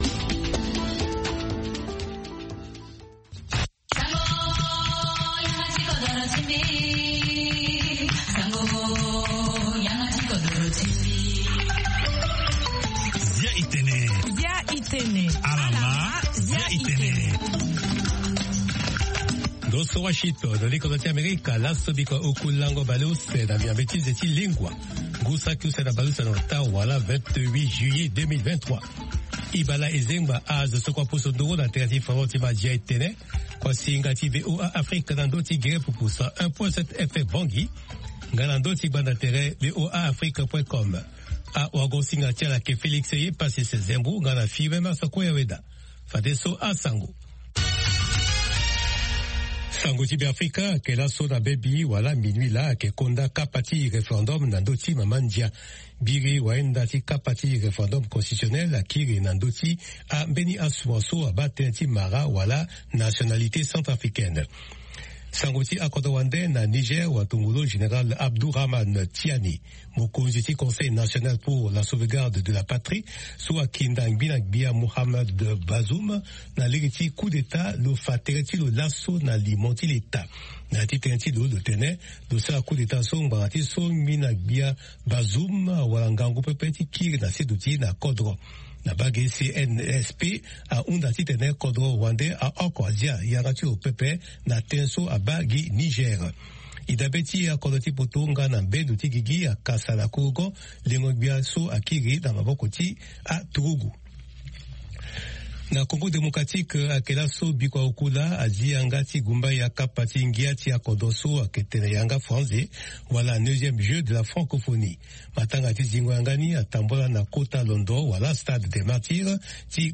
Zia i Tene, est un programme en sango comportant plusieurs rubriques sur l'actualité nationale et internationale, des interviews portant sur une analyse et des réactions sur des sujets divers ainsi qu'un volet consacré aux artistes.